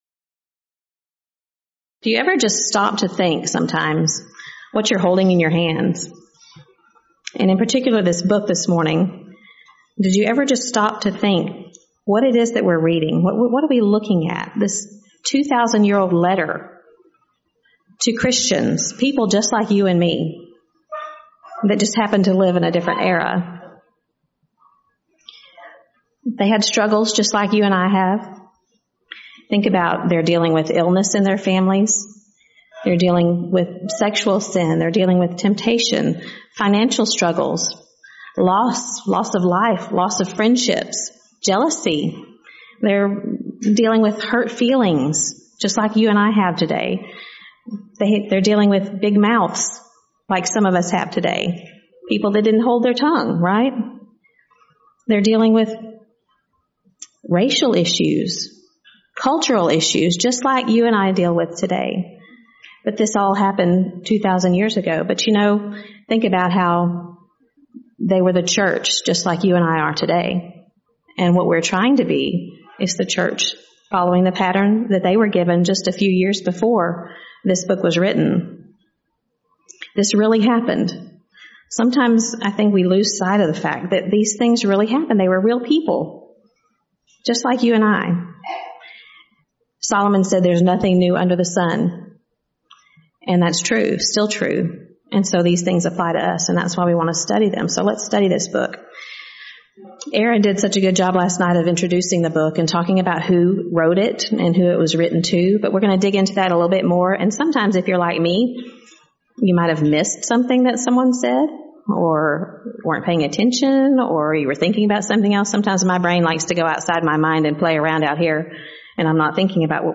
Event: 5th Annual Texas Ladies In Christ Retreat
Ladies Sessions